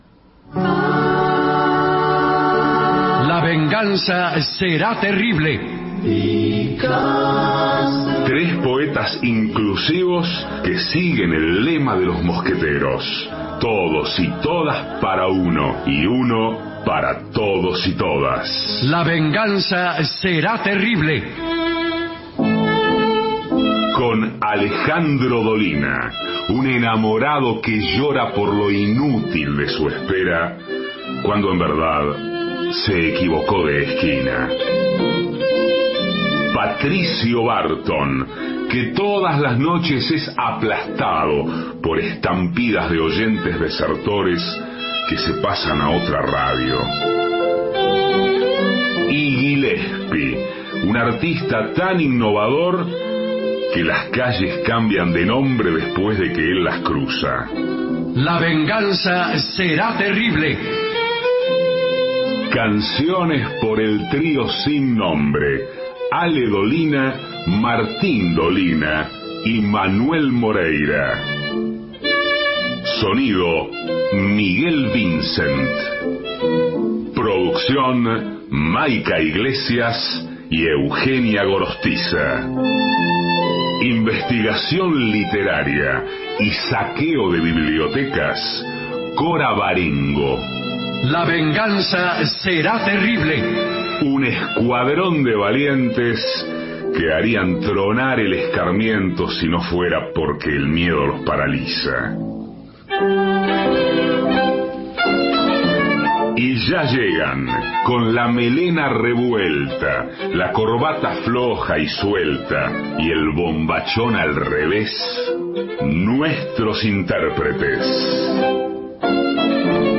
Estudios Radio AM 750 Alejandro Dolina